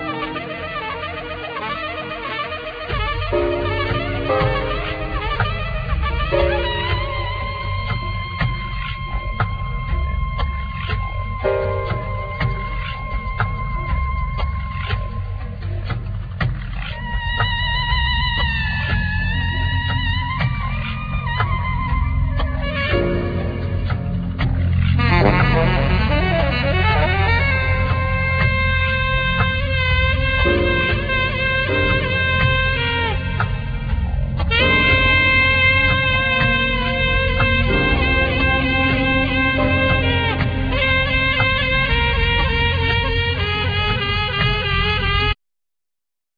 Fender Bass,Double Bass
Keyboards,Organ,Grand Piano
Saxophne
Percussion